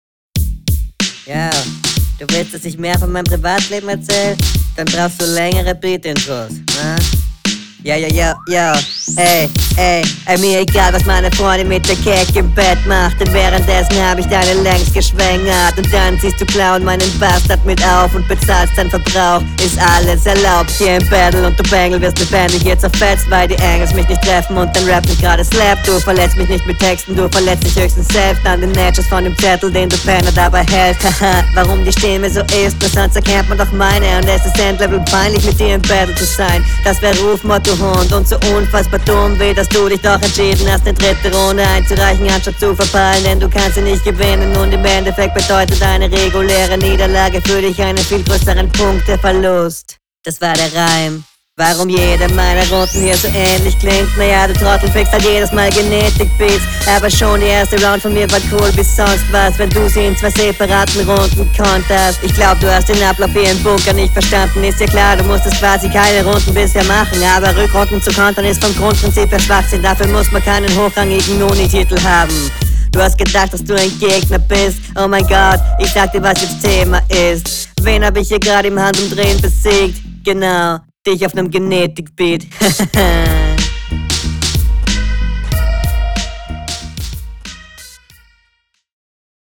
Flow auch cool, Mix scheiße, Punches sind gut, Reime meistens auch
Gut gekontert, Audio suckt ass …